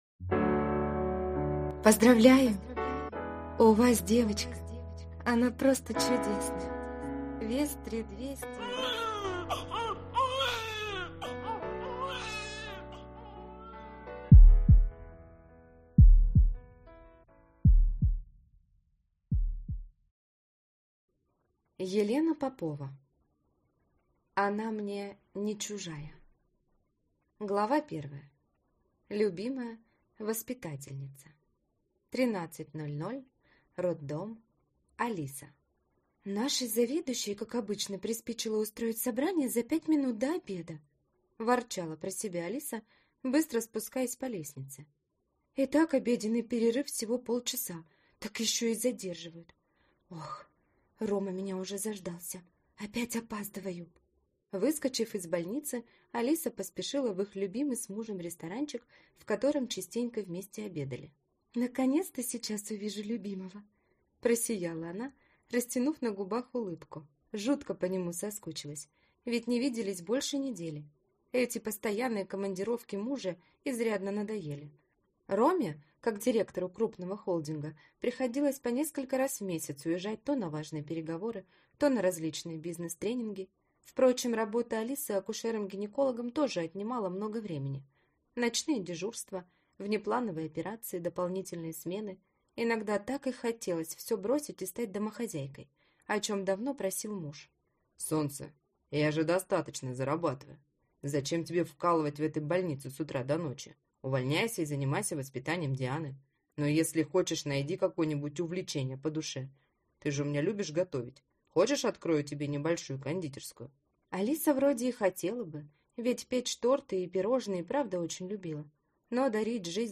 Аудиокнига Она мне (не) чужая | Библиотека аудиокниг